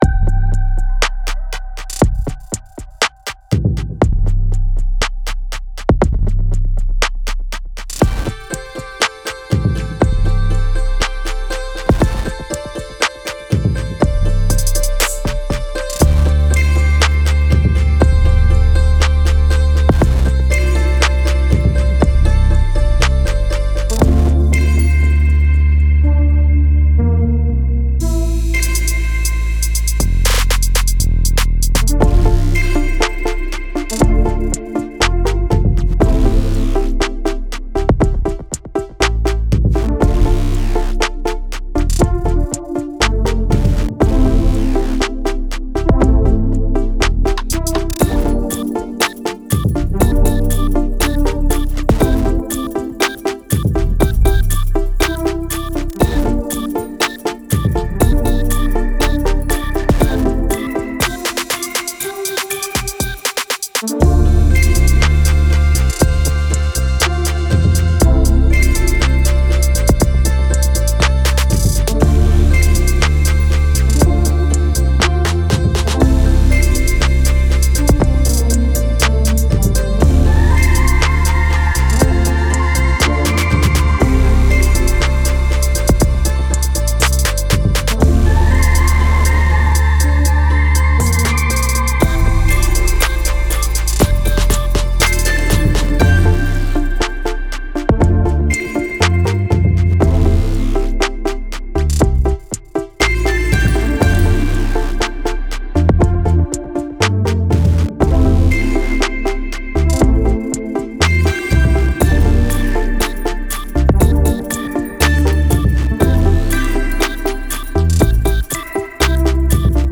Genres: Hip-hop and rap